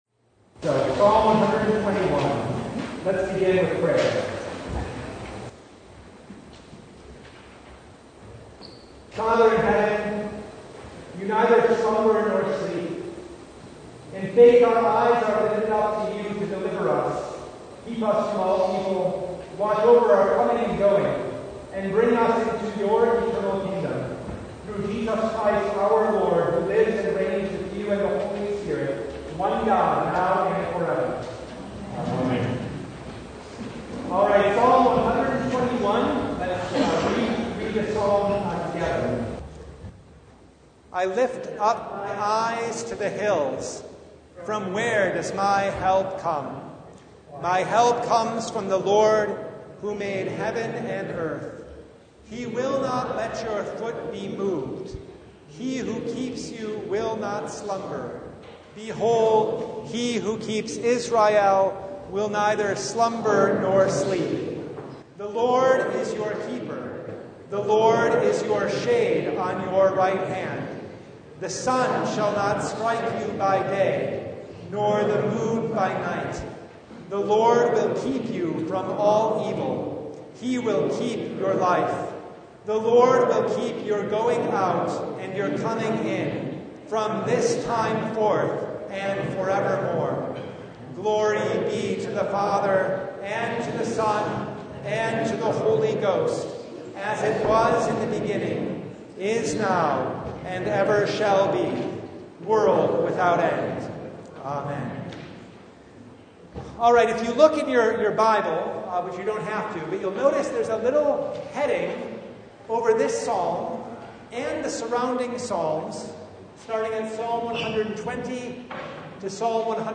Psalm 121 Service Type: Bible Study I lift up my eyes to the hills.